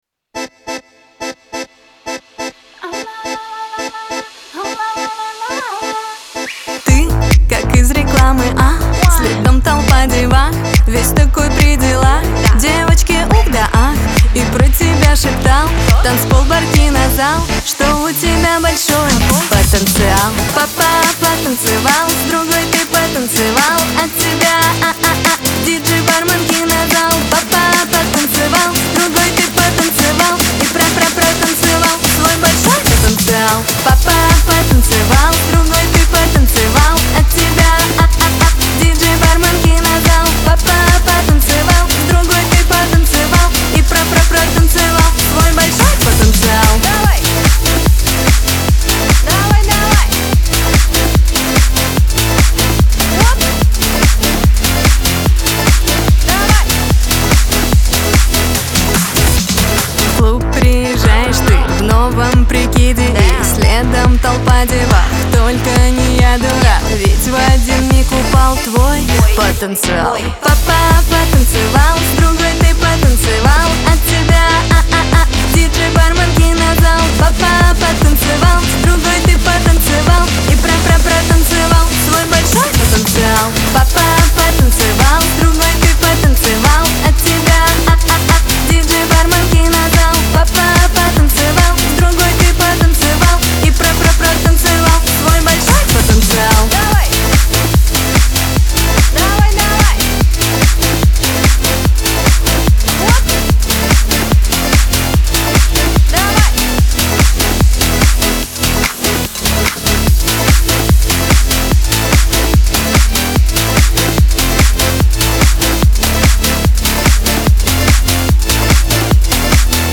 dance
Веселая музыка , pop